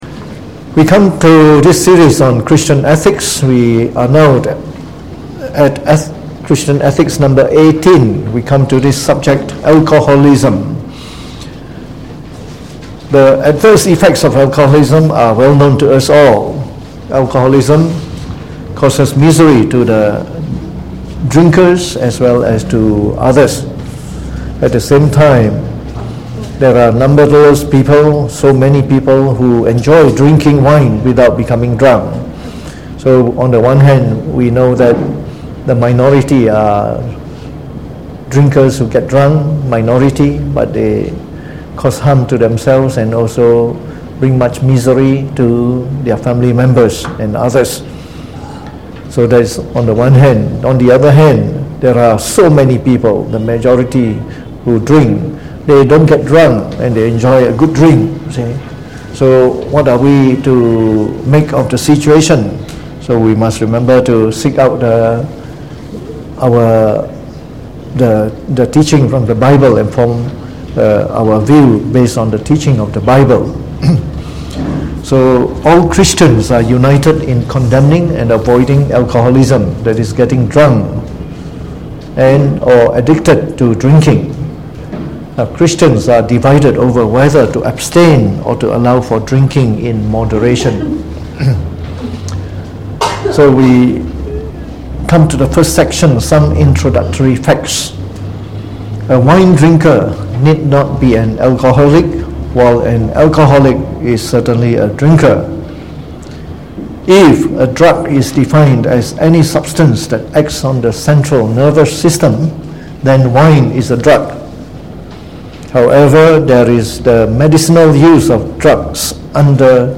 Delivered on the 10th of July 2019 during the Bible Study, from our series on Christian Ethics.